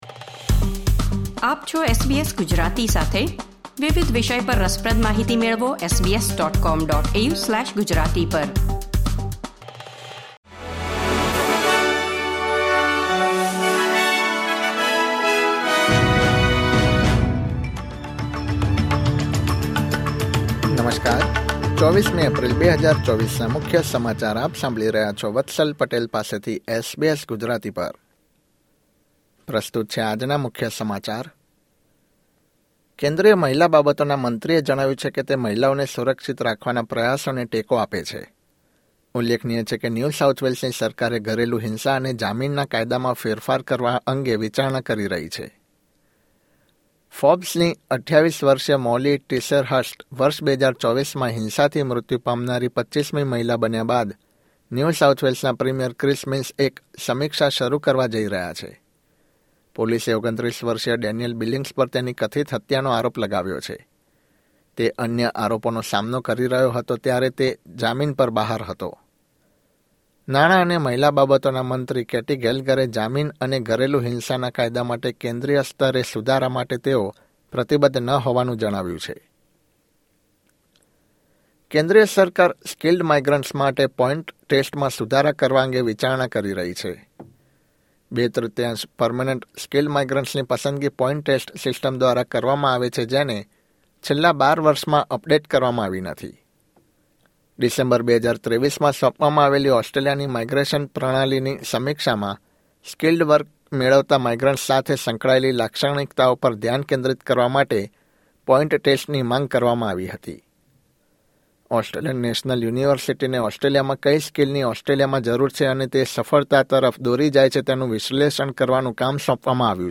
૨૪ એપ્રિલ ૨୦૨૪ના મુખ્ય સમાચાર